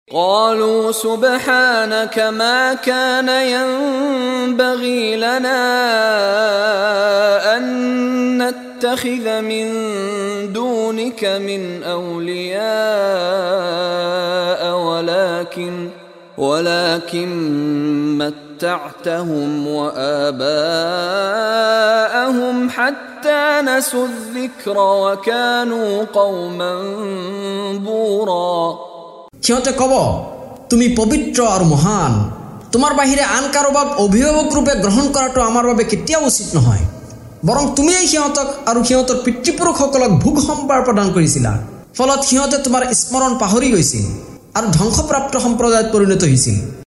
লগতে ক্বাৰী মিশ্বাৰী ৰাশ্বিদ আল-আফাছীৰ কণ্ঠত তিলাৱত।